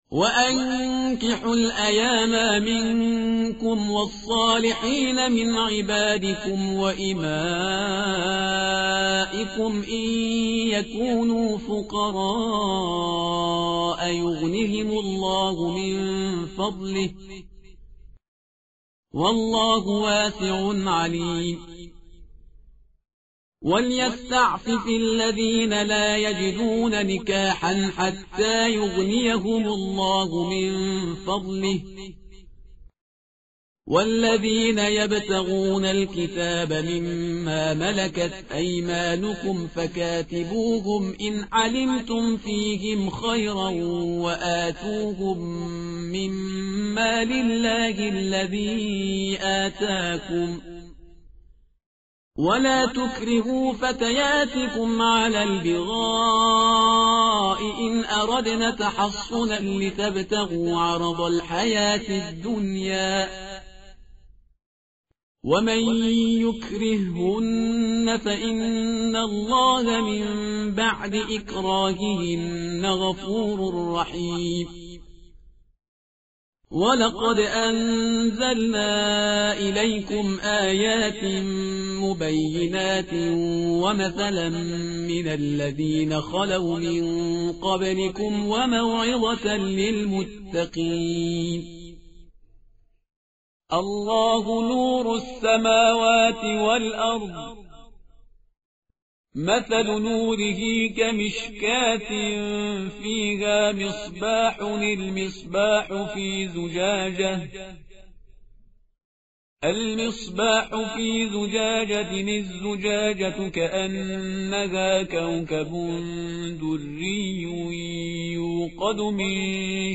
tartil_parhizgar_page_354.mp3